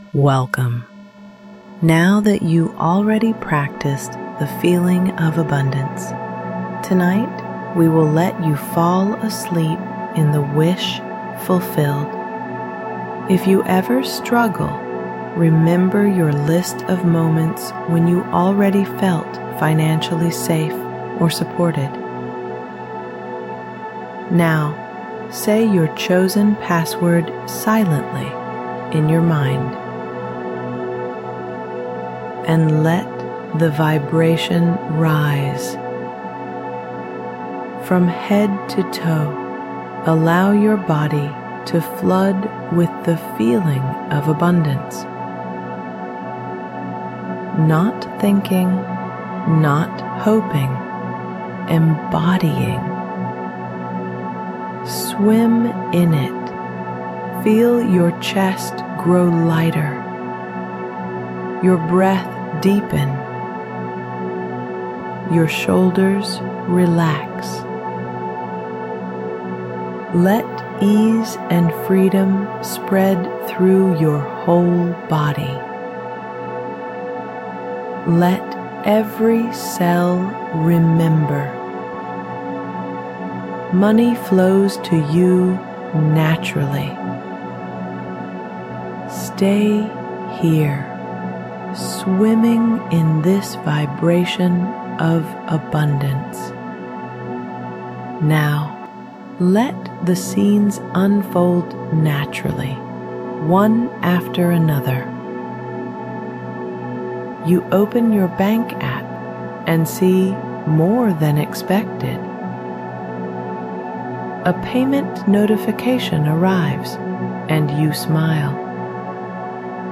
Female Voiced Meditation